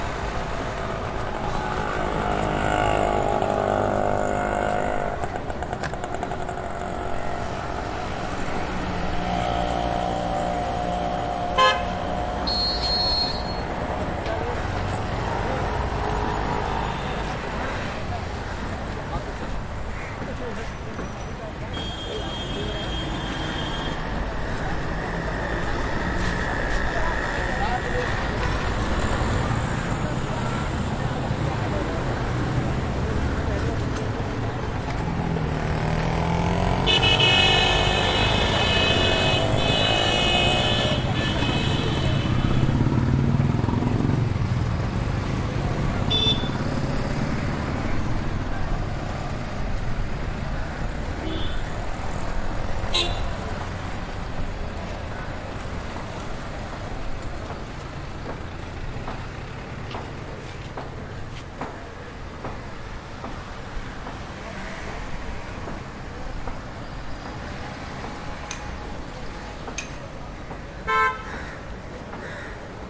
印度 " 红绿灯 袅袅婷婷的一对漂亮的摩托车通过缓慢而紧密的喇叭声 印度
描述：交通灯嘶哑夫妇的好摩托车通过慢和关闭喇叭鸣喇叭.flac
Tag: 关闭 传球 摩托车 交通 喇叭 印度 honks 沙哑